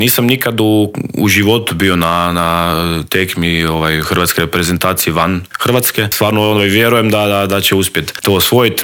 Vrhunski hrvatski gimnastičar bio je gost Intervjua tjedna Media servisa.